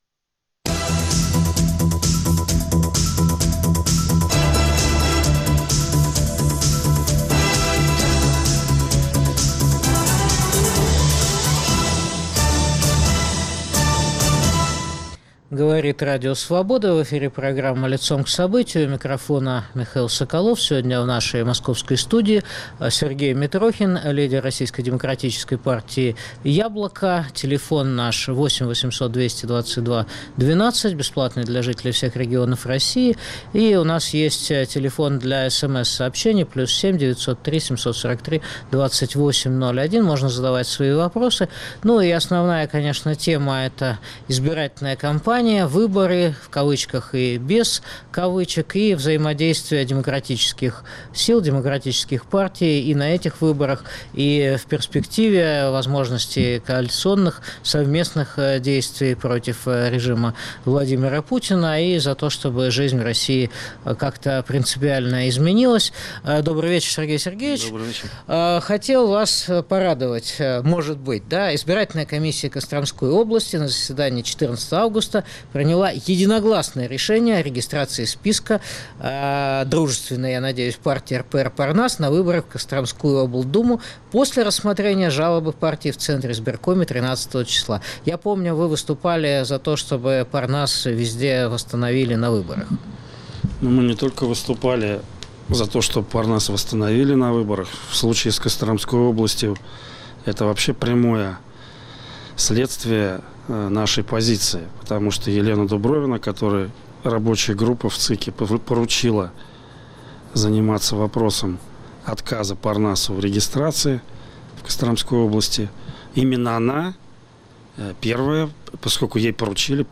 В студии Радио Свобода лидер партии "Яблоко" Сергей Митрохин.